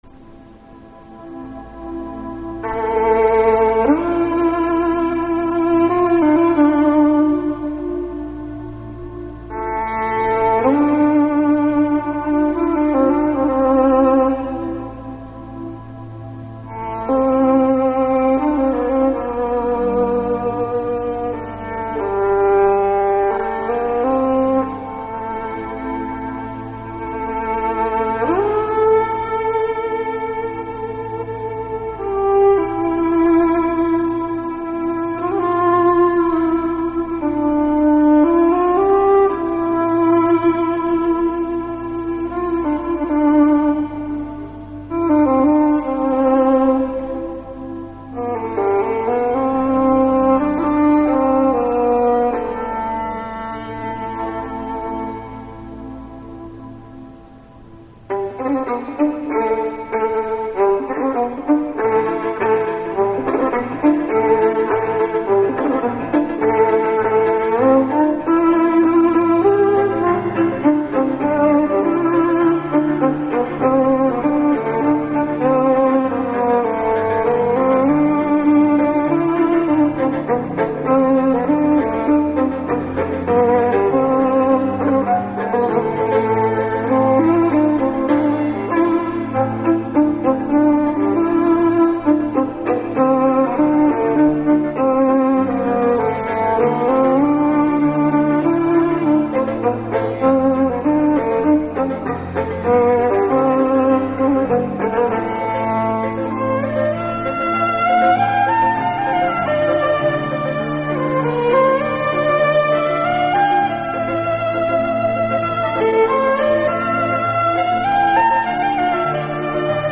قطعه بی کلام